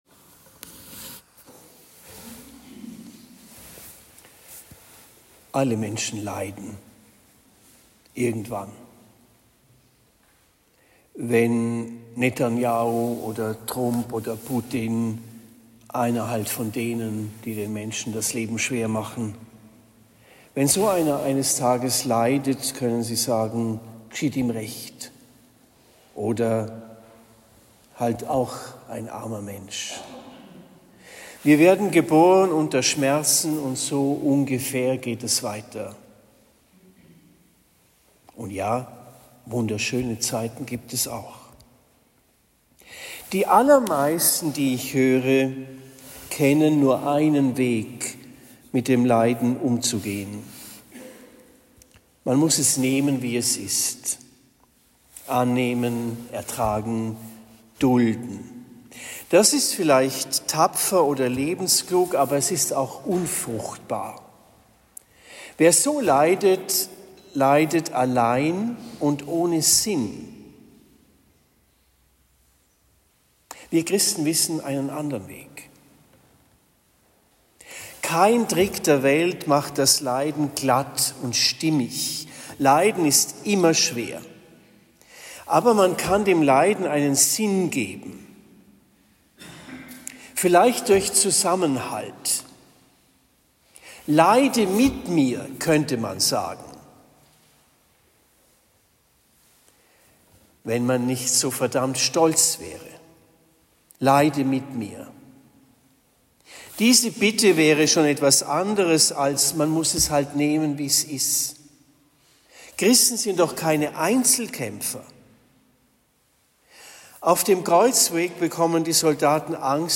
Karfreitag 2025 Predigt am 18. April 2025 in Trennfeld